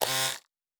pgs/Assets/Audio/Sci-Fi Sounds/Mechanical/Servo Small 5_1.wav at master
Servo Small 5_1.wav